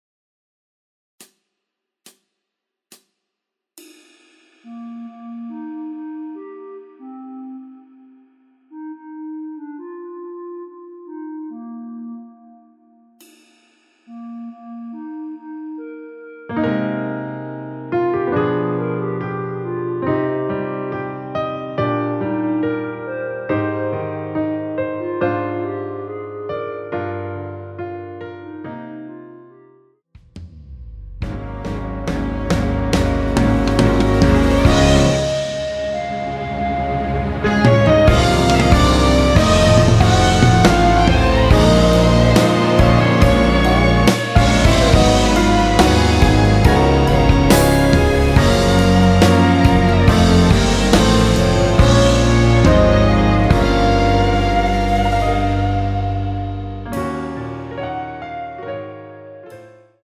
원키에서(-7)내린 멜로디 포함된 MR입니다.
Eb
앞부분30초, 뒷부분30초씩 편집해서 올려 드리고 있습니다.
중간에 음이 끈어지고 다시 나오는 이유는